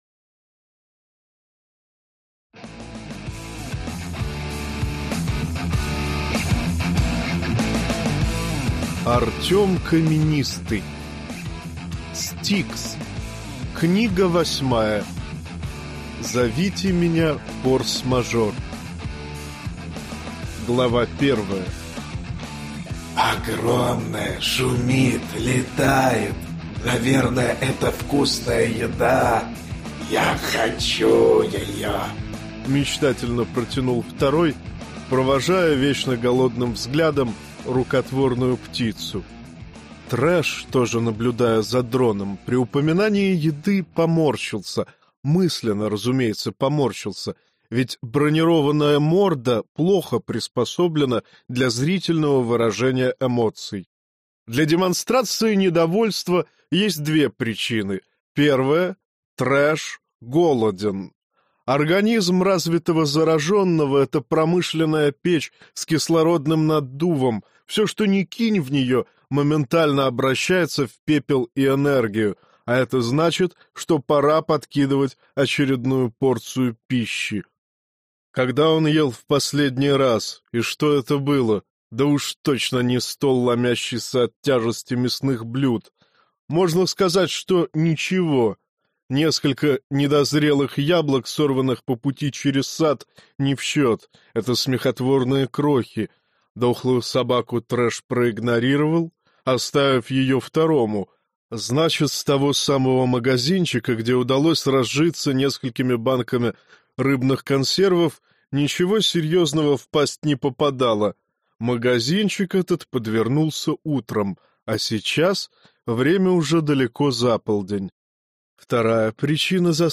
Аудиокнига S-T-I-K-S. Зовите меня форс-мажор - купить, скачать и слушать онлайн | КнигоПоиск